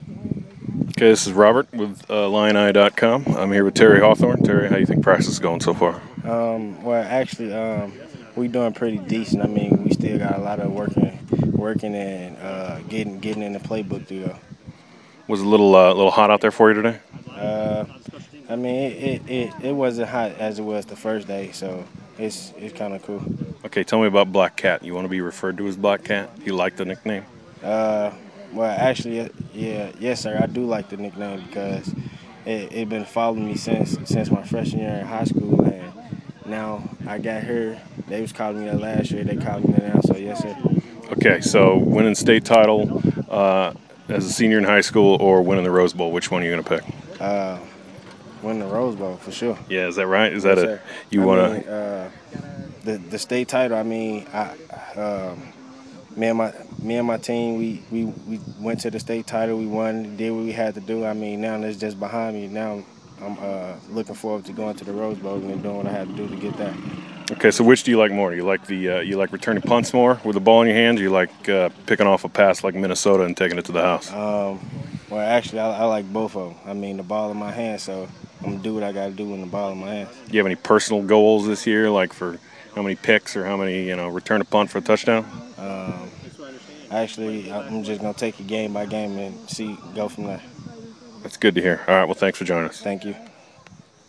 Post Practice Interview